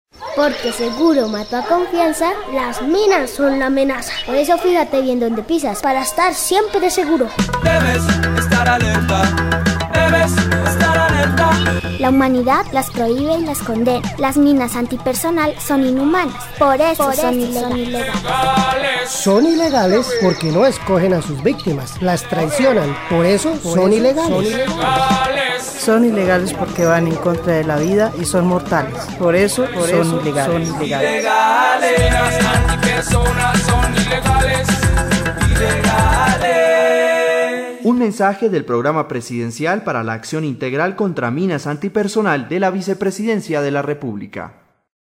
La campaña incluye siete comerciales de televisión sobre los riesgos y las orientaciones para asumir comportamientos seguros; cinco cuñas radiales con los mensajes básicos de prevención y las voces de importantes artistas como Maia y el grupo San Alejo, entre otros.
Cuñas radiales